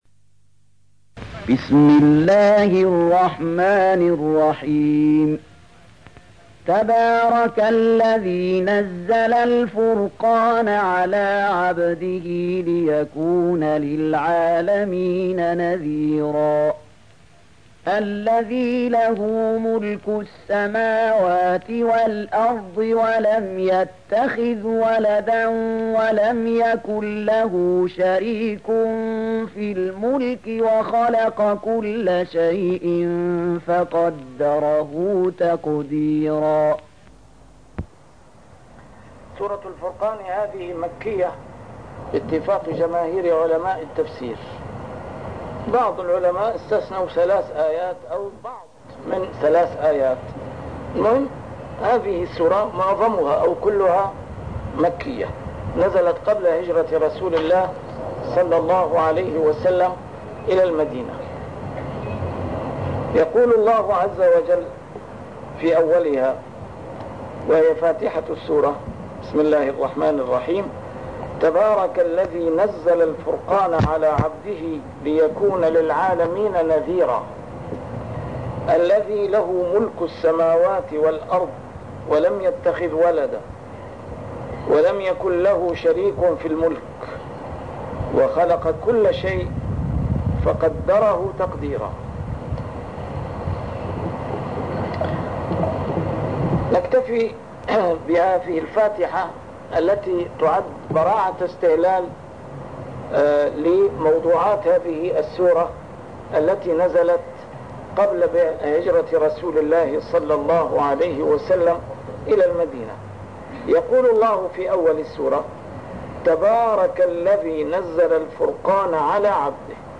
A MARTYR SCHOLAR: IMAM MUHAMMAD SAEED RAMADAN AL-BOUTI - الدروس العلمية - تفسير القرآن الكريم - تسجيل قديم - الدرس 201: الفرقان 01-02